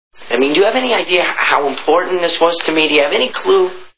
Back to the Future Movie Sound Bites